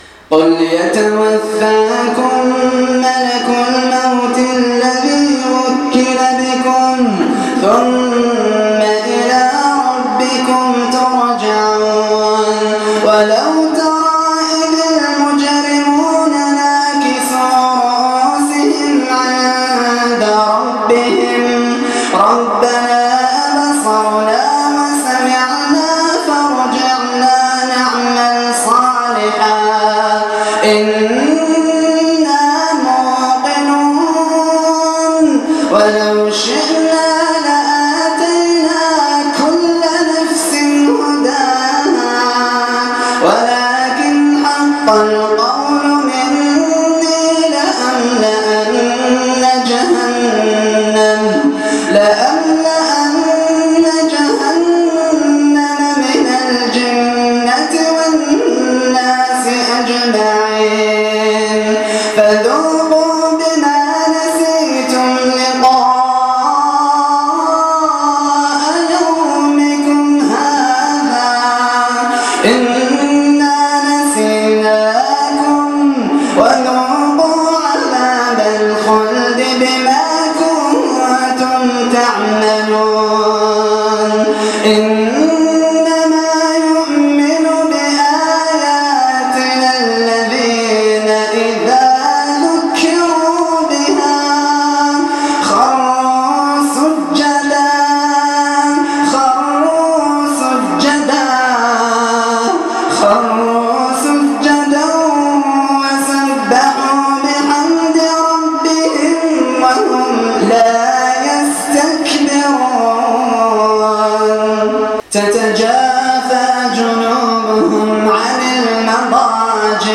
تلاوة خاشعة ومؤثرة من صلاة التراويح